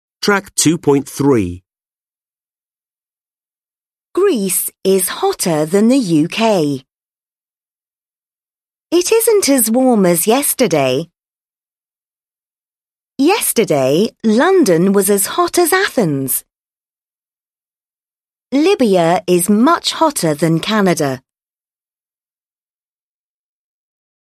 The pronunciation of than and as in the sentences: weak.